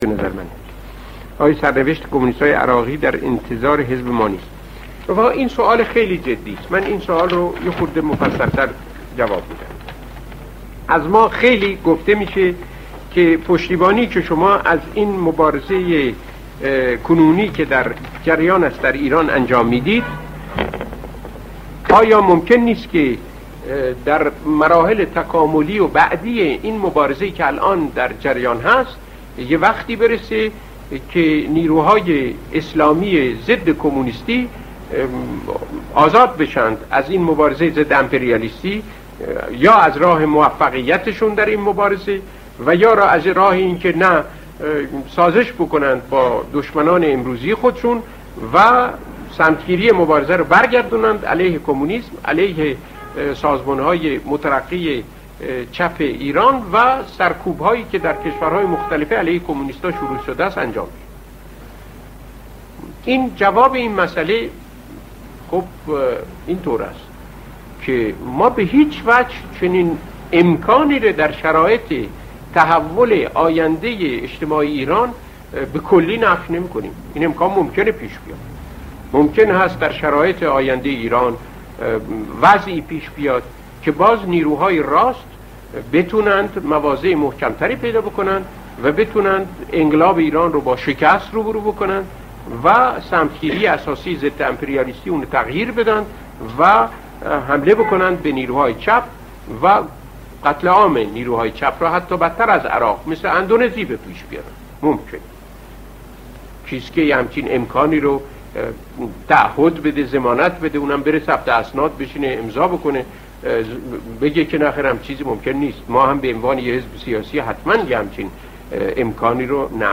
مصاحبه کیانوری درباره